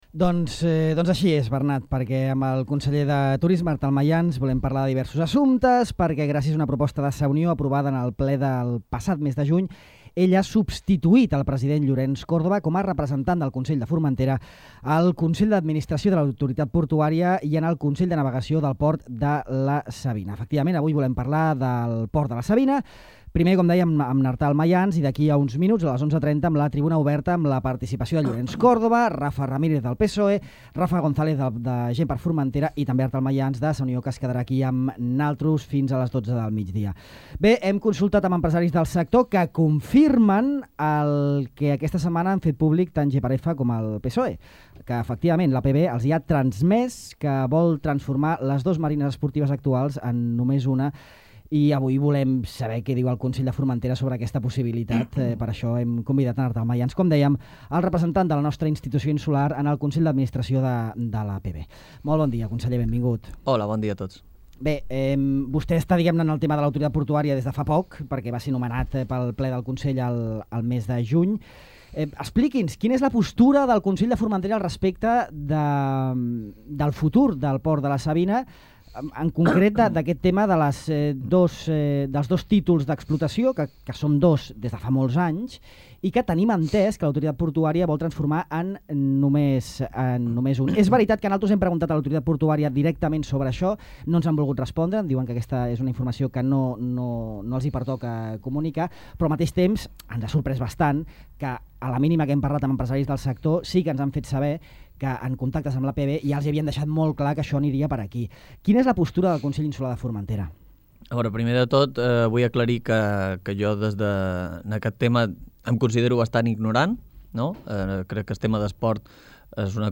El conseller de Turisme, Artal Mayans (Sa Unió), ha explicat en aquesta entrevista a Ràdio Illa que tot apunta que en un pròxim Consell d’Administració de l’Autoritat Portuària de Balears (APB) s’hi votarà l’aprovació de les bases per al concurs de la futura explotació dels amarraments esportius del port de la Savina.